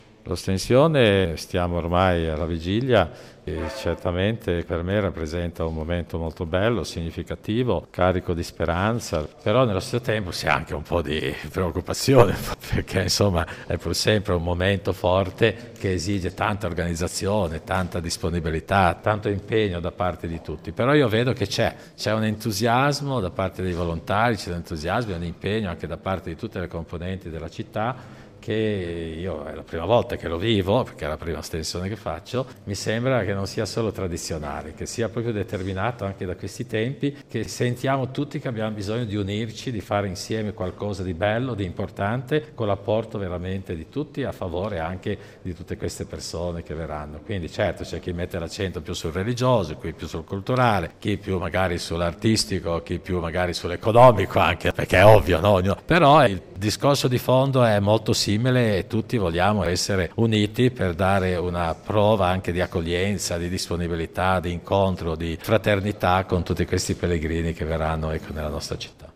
Così l’arcivescovo monsignor Cesare Nosiglia alla vigilia dell’evento che vedrà a Torino, dal 19 aprile, una grandissima folla di fedeli e pellegrini (in allegato la dichiarazione ai microfoni di Primaradio).
nosiglia-vigilia-ostensione.mp3